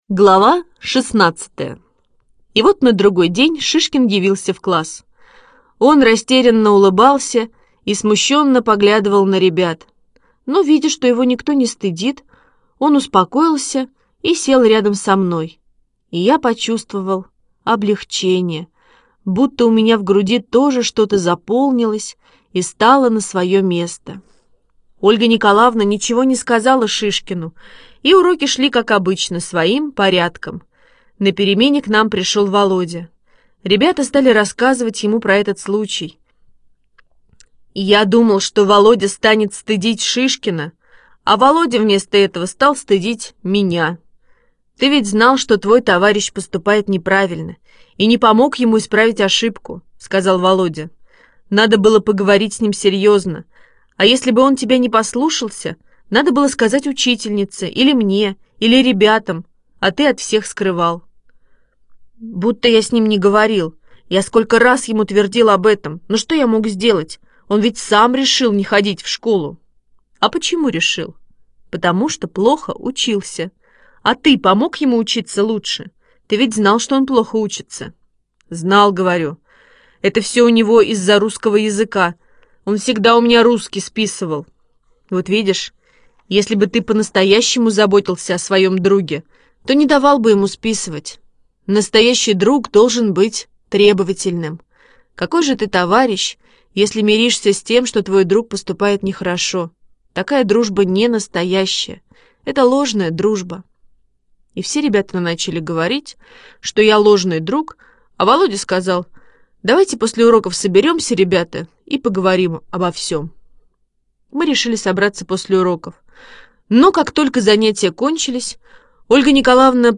Аудиосказка Витя Малеев в школе и дома слушать онлайн